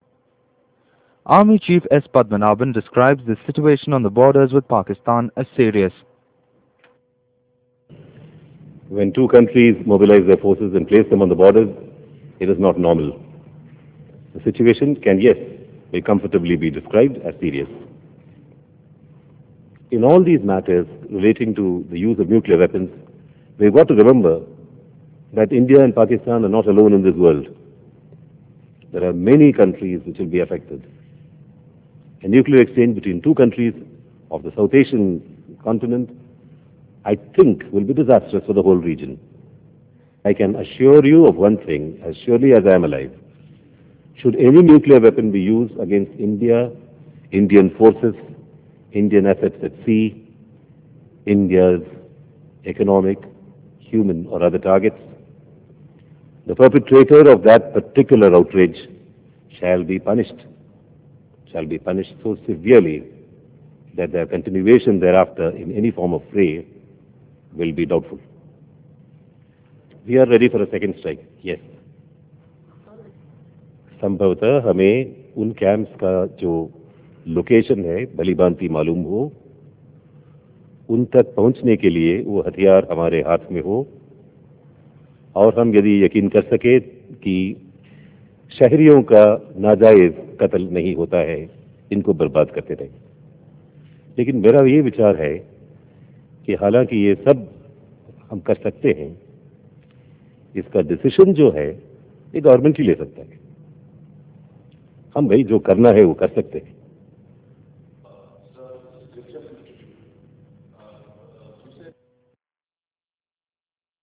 Gen. Padmanabhan addresses a Press conference in Delhi on Friday.